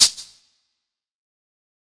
027_Lo-Fi Shaker Hi-Hat.wav